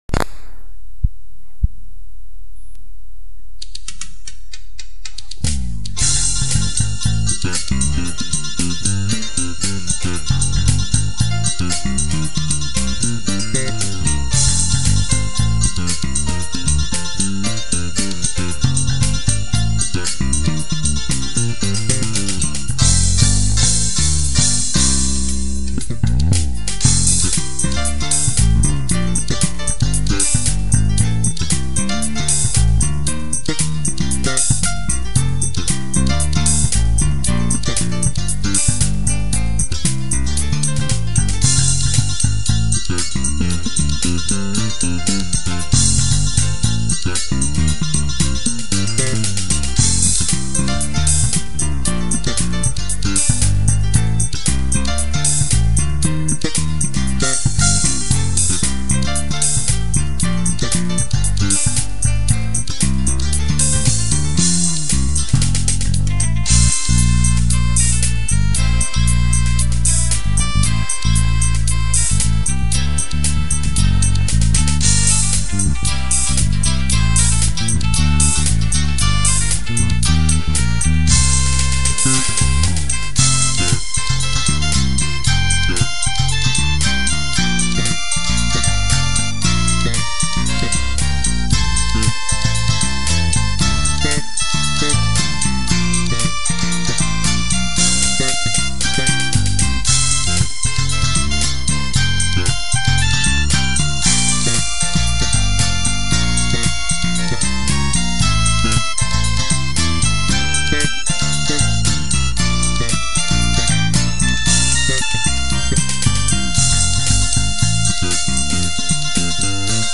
베이스^^
2006-08-17 톤에서 약간 아쉬운감이있네요,, 멋있습니다!